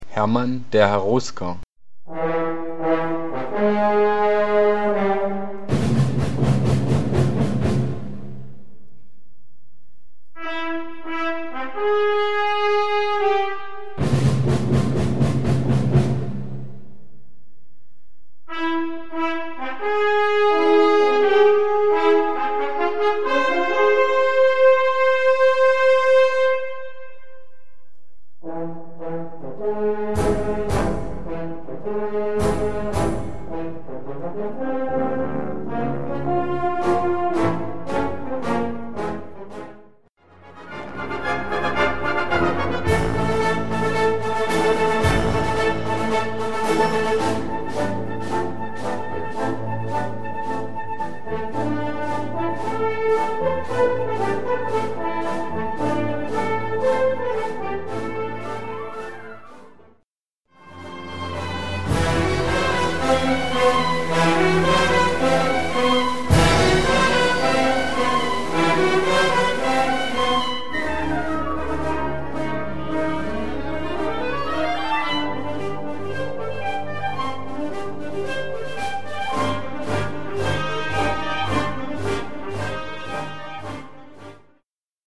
Gattung: Overture für Blasorchester
Besetzung: Blasorchester